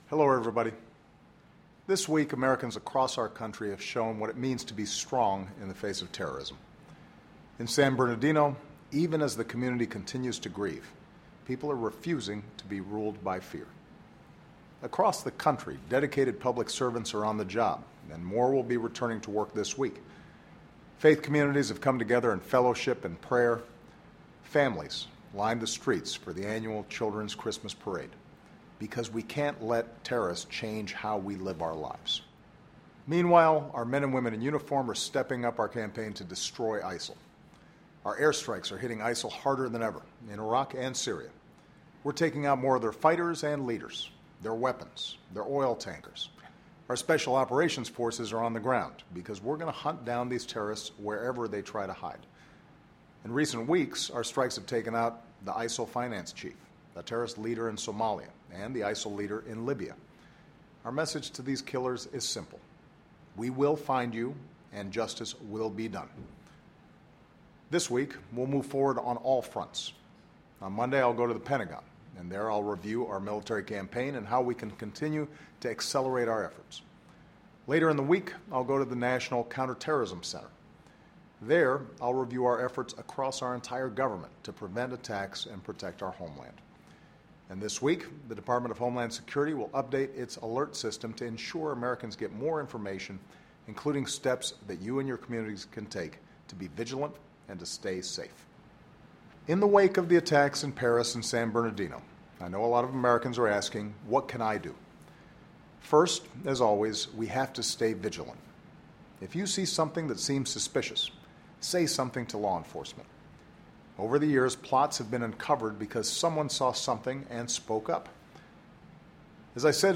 Weekly Address: Standing Strong in the Face of Terrorism